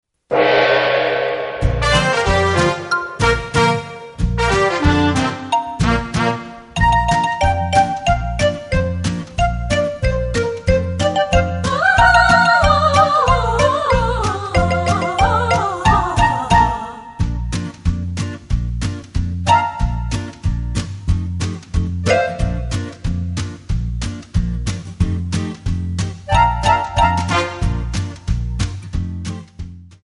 Bb
MPEG 1 Layer 3 (Stereo)
Backing track Karaoke
Pop, Oldies, 1950s